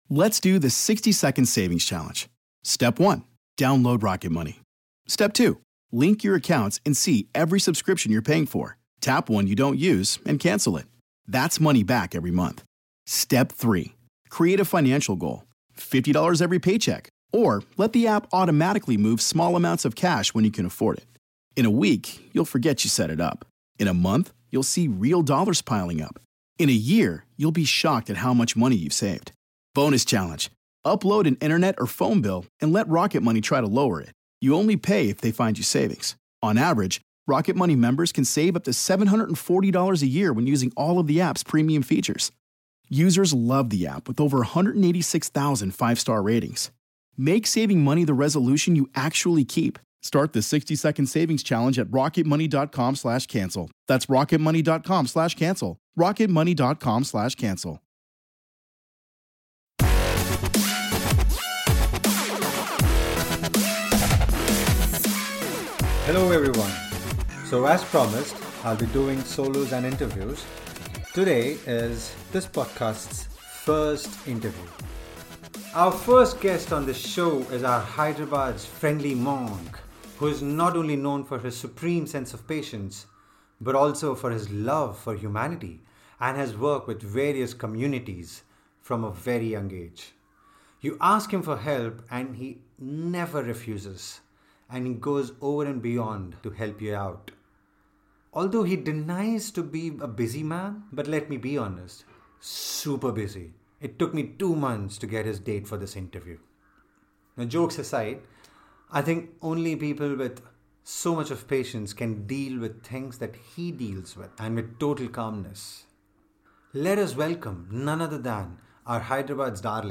It's Your Postman / Interview